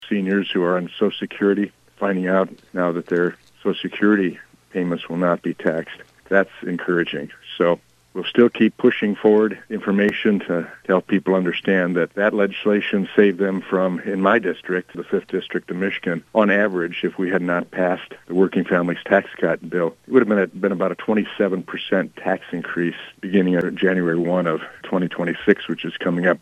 That was Congressman Tim Walberg.